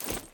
take_item2.ogg